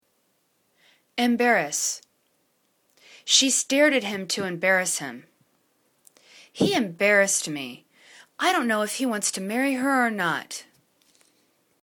em.bar.rass     /im'barəs/    n